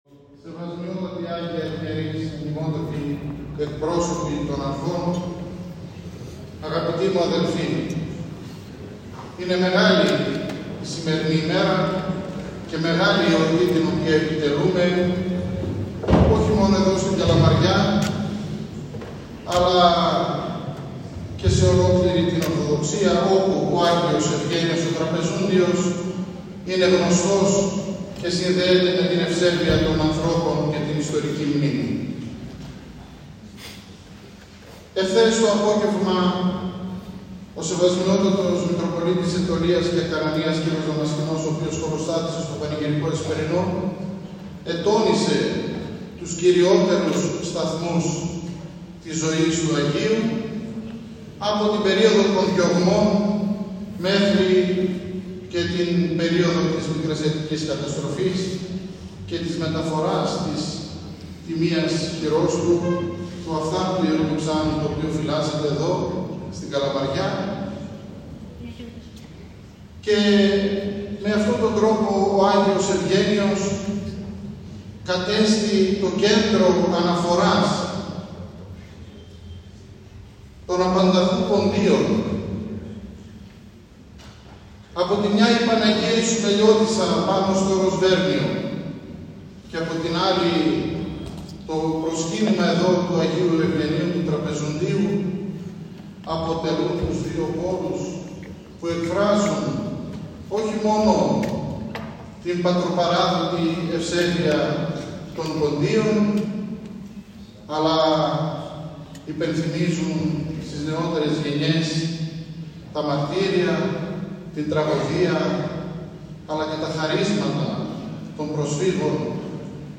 Φωτορεπορτάζ ope: Τον «άστεγο» Πολιούχο της, Άγιο Ευγένιο, εόρτασε η Καλαμαριά
Ακούστε το κήρυγμα του Μητροπολίτου Κίτρους κ. Γεωργίου.